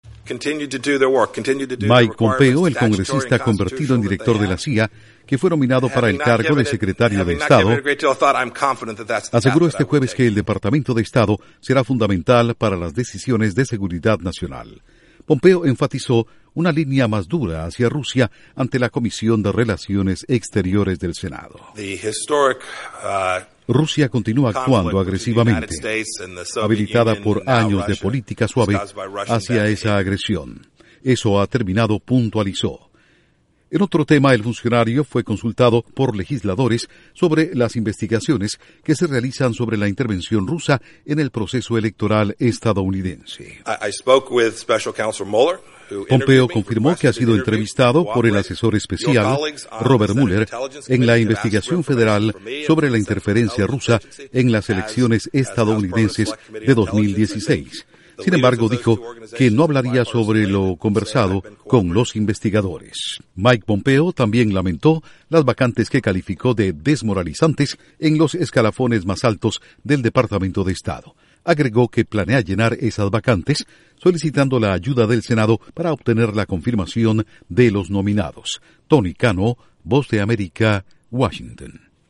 Con 3 audios de Mike Pompeo /Nominado a Secretario de Estado .